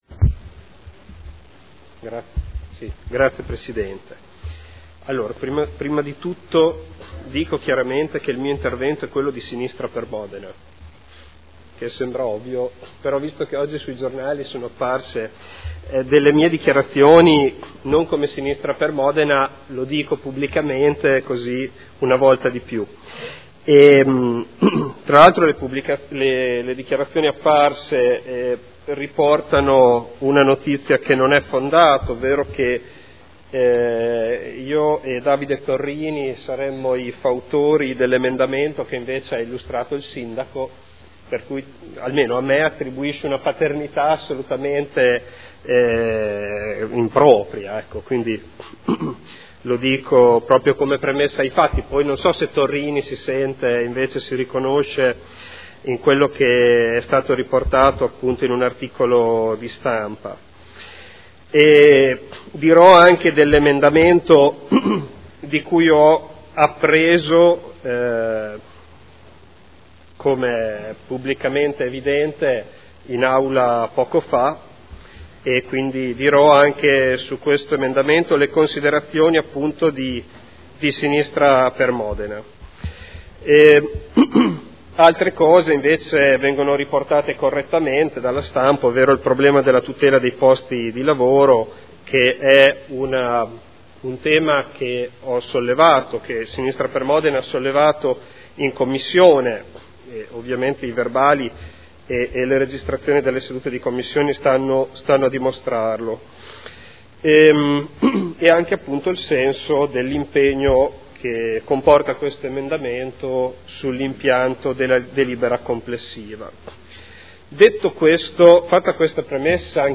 Dibattito su emendamento e proposta di deliberazione. Fotomuseo Panini e Fondazione Fotografia - Indirizzi per la costituzione di una nuova Fondazione